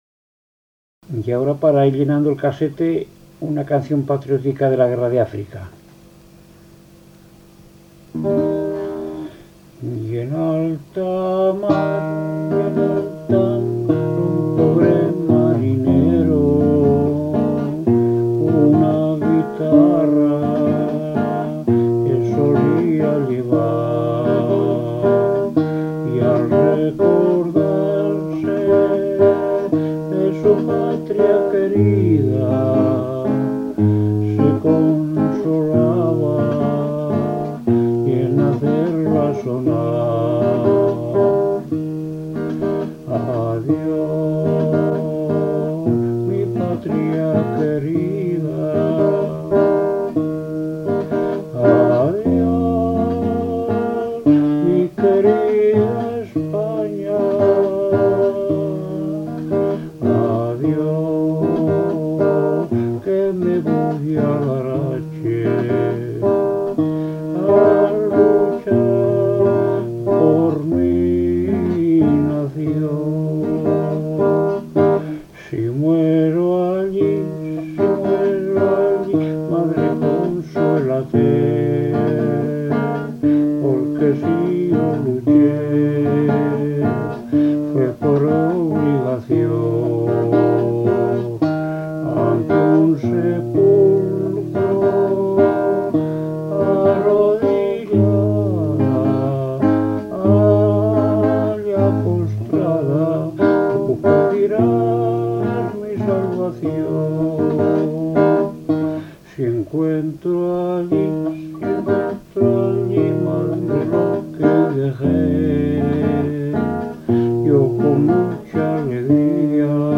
Clasificación: Cancionero
Lugar y fecha de grabación: Barcelona, 12 de diciembre de 1993